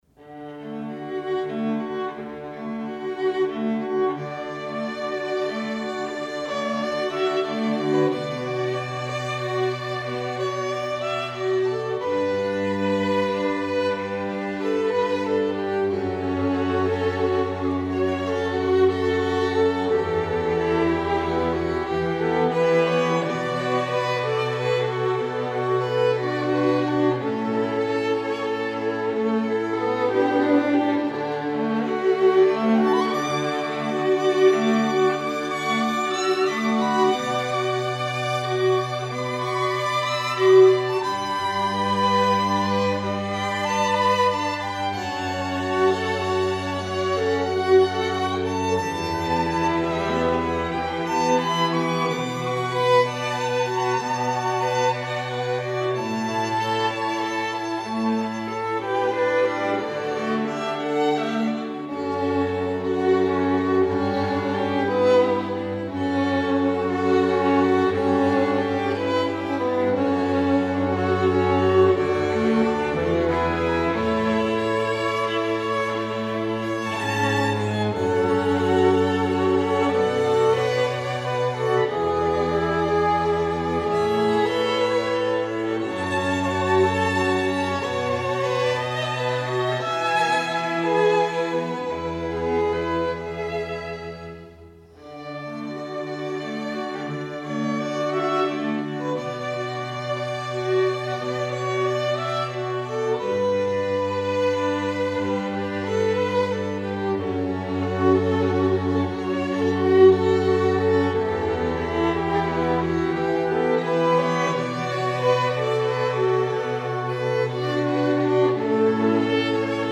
Strings
Each player of this string quartet brings a diversity of experience, from studies with world renown teachers, to performances with great orchestras.